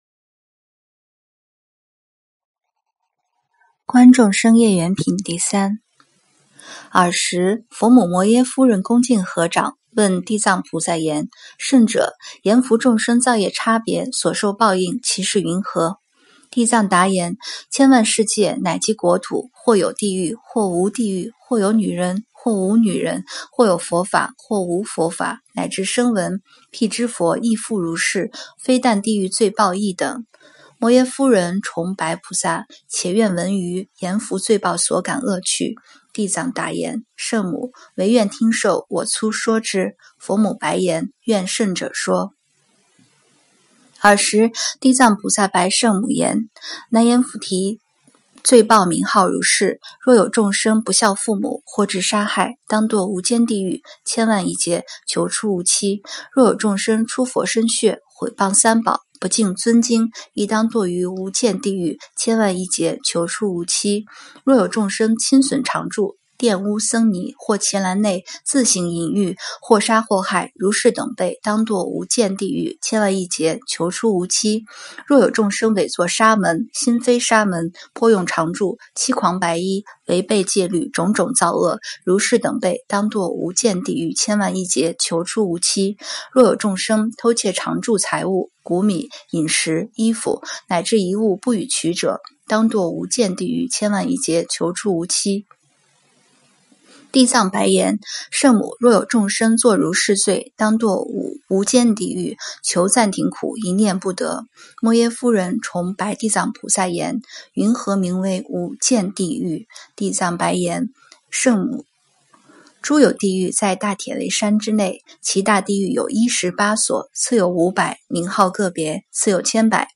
经忏
标签: 佛音经忏佛教音乐